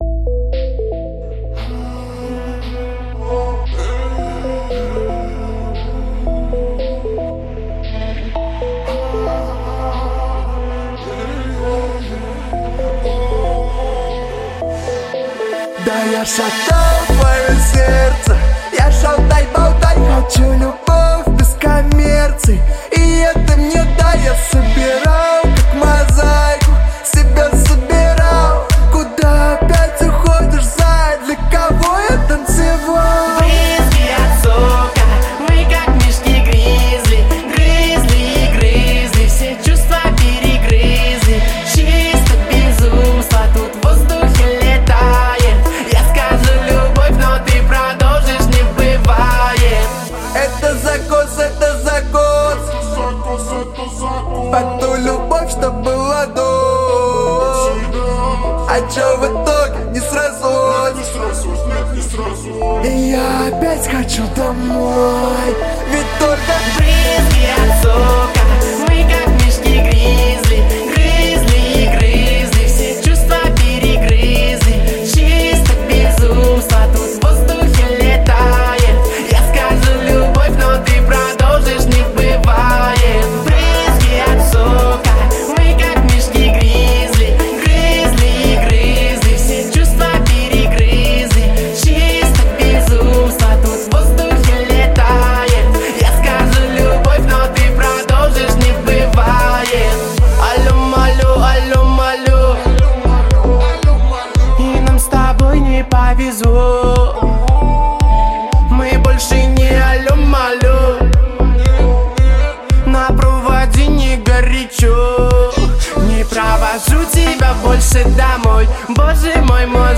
• Жанр песни: Жанры / Поп-музыка